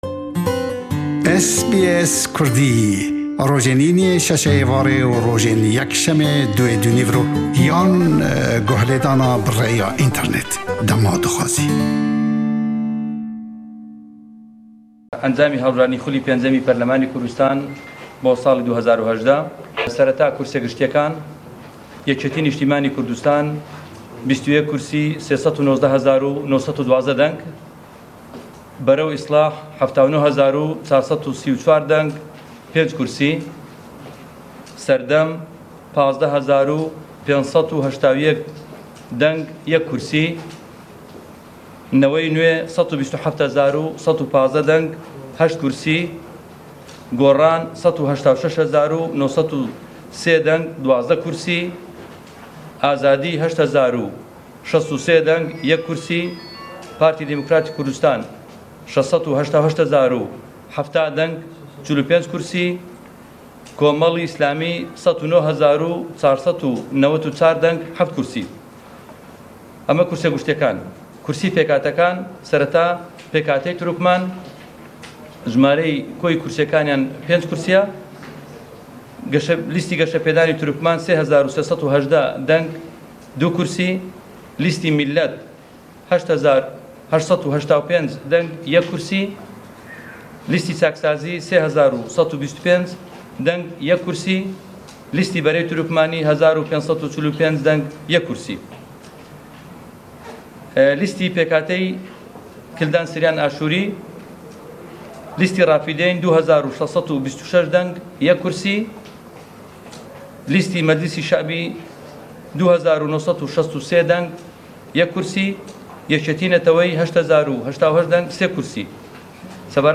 Raportî peyamnêrman